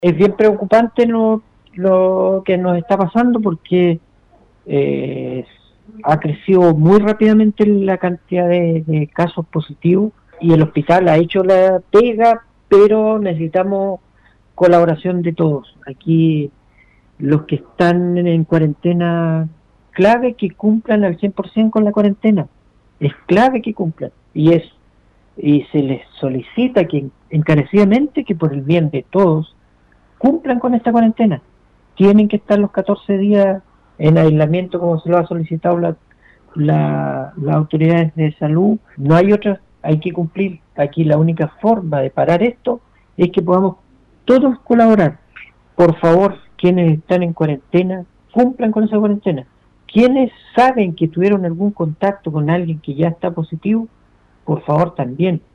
Al respecto, se mostró con profunda preocupación el alcalde de Palena Ricardo Soto, ante la situación de aumento de casos en la comuna, en entrevista con radio Estrella del Mar de esa comuna.